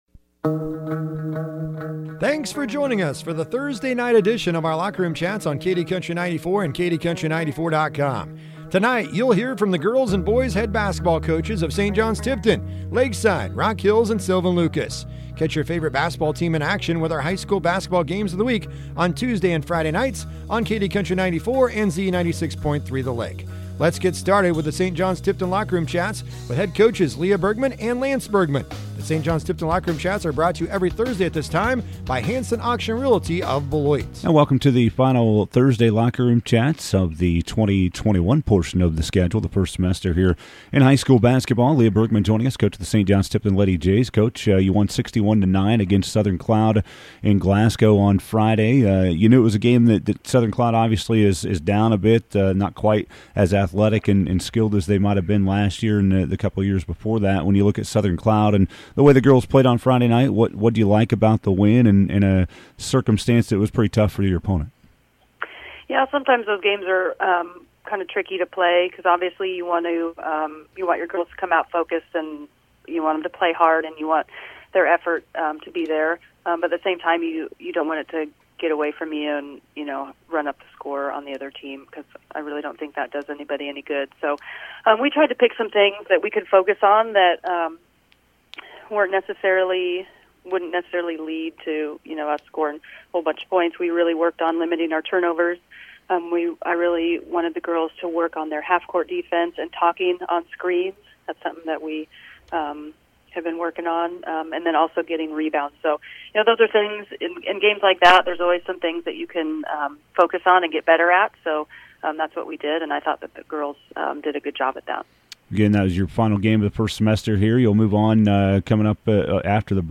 12-23-21 High School Basketball Locker Room Chats